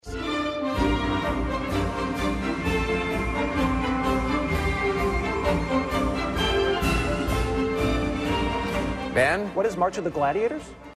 A Colosseum occupation, it's also the title of the Sousa march heard